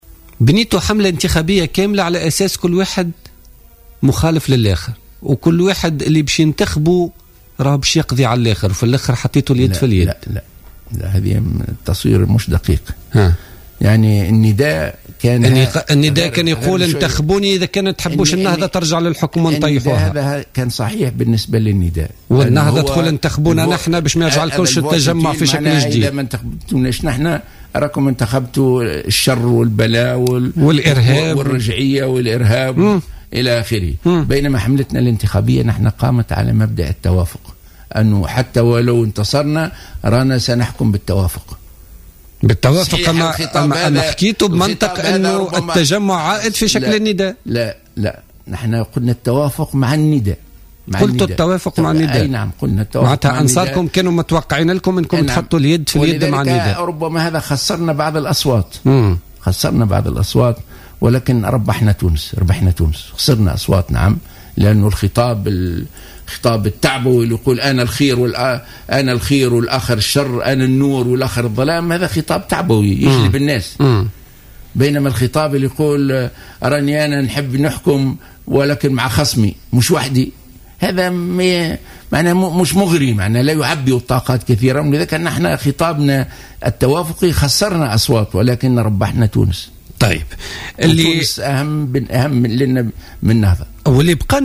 وأكد الغنوشي في حوار حصري مع الجوهرة أف أم اليوم الخميس أن النهضة اختارت منذ البداية طريق التوافق وأكدت قبل الانتخابات أنها لن تحكم إلا بالتوافق وإن حققت الاغلبية، وهو موقف دافعت عنه الحركة على الرغم من أنه تسبب في خسارتها لعديد الأصوات.